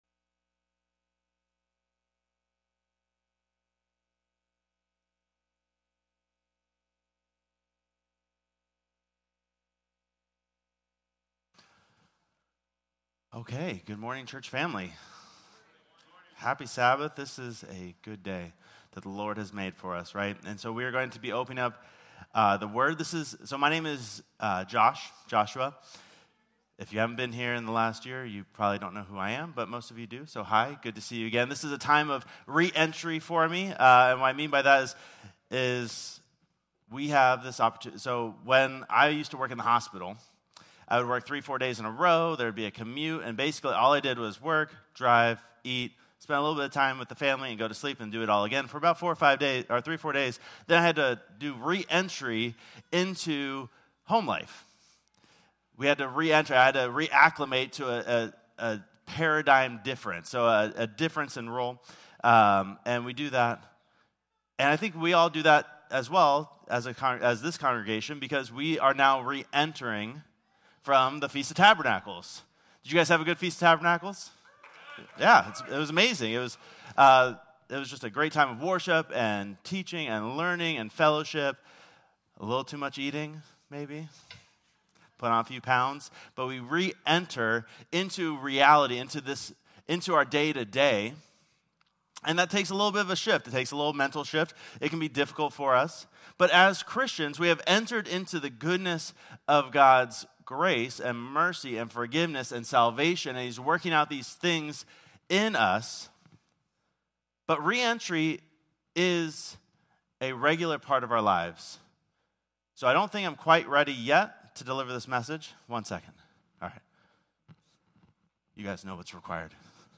Whether it’s fear, doubt, past failures, or simply not knowing where to start, this sermon will help you identify and overcome the barriers standing between you and God’s best for your life.